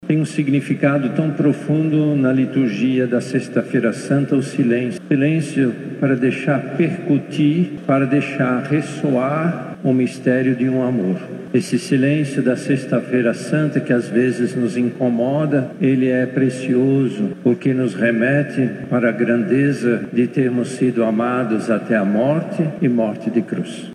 Às 15h, conhecida como a “hora da agonia”, foi realizada a Celebração da Paixão do Senhor no Santuário de Fátima.
Após a leitura da Paixão, o Cardeal Steiner iniciou sua homilia, destacando o silêncio e a contemplação vividos pela comunidade neste dia santo.